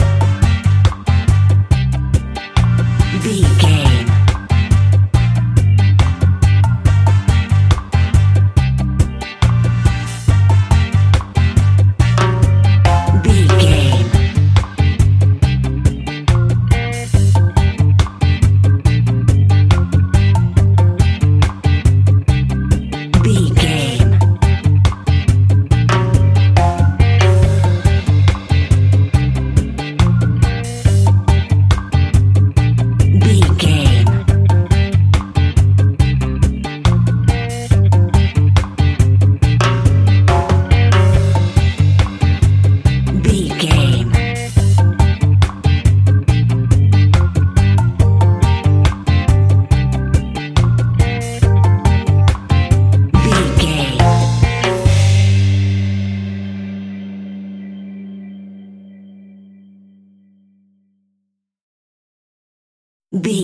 Classic reggae music with that skank bounce reggae feeling.
Aeolian/Minor
laid back
chilled
off beat
skank guitar
hammond organ
percussion
horns